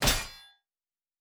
Fantasy Interface Sounds
Weapon UI 05.wav